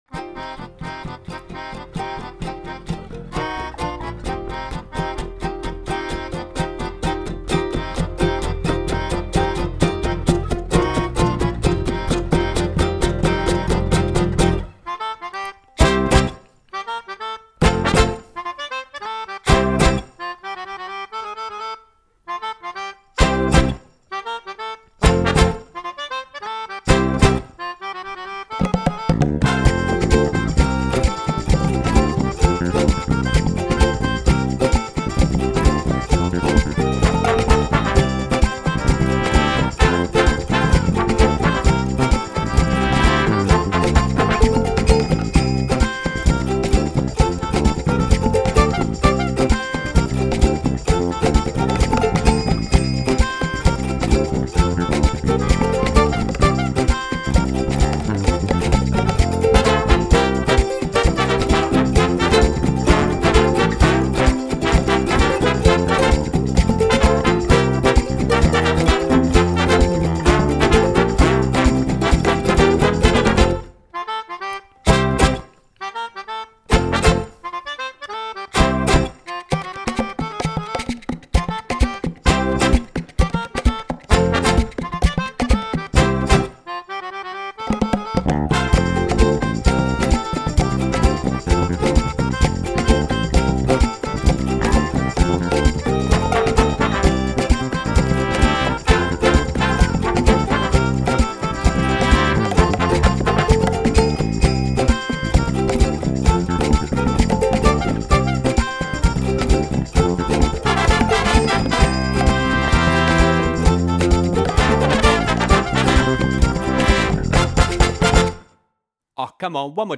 karaoke version too!)